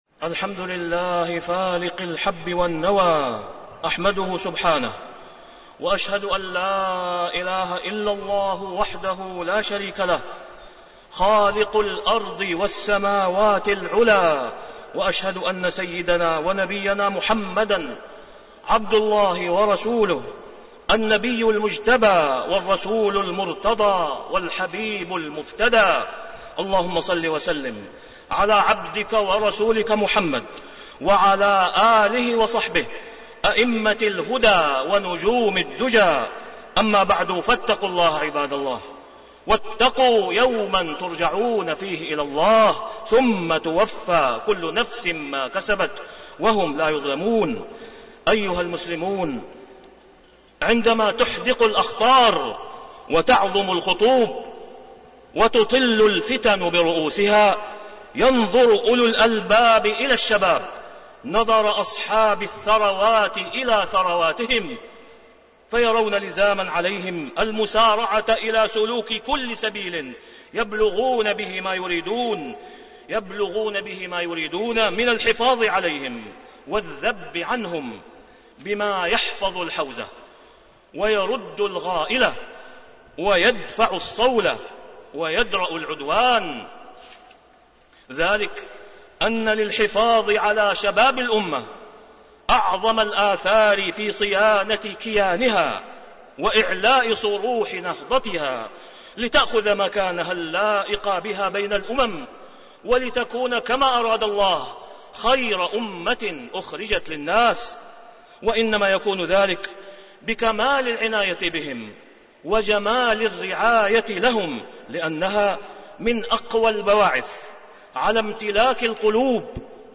تاريخ النشر ١٠ جمادى الآخرة ١٤٣٢ هـ المكان: المسجد الحرام الشيخ: فضيلة الشيخ د. أسامة بن عبدالله خياط فضيلة الشيخ د. أسامة بن عبدالله خياط الجليس الصالح والجليس السوء